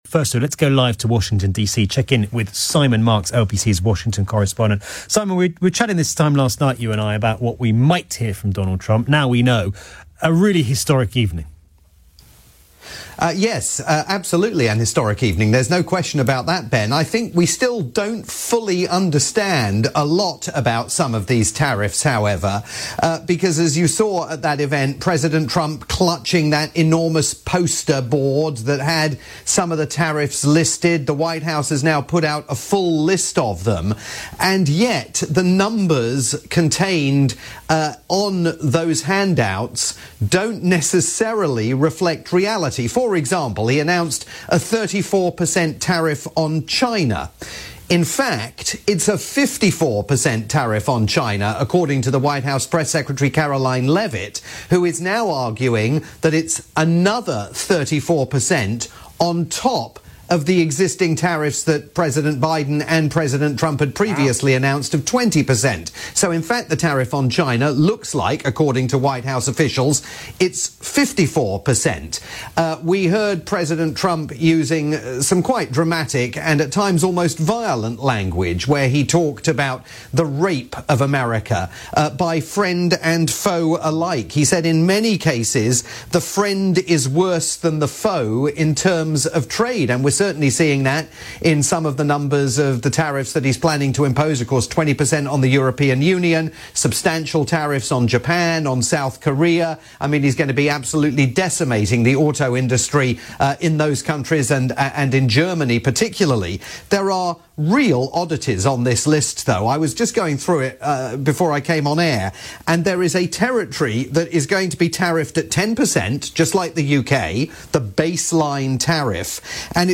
late night programme on the UK's LBC.